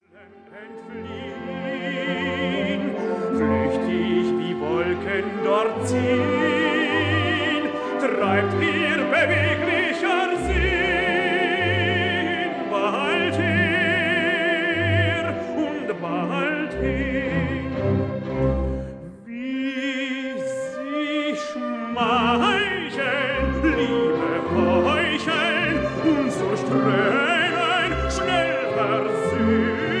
Stereo recording made in Berlin, July 1960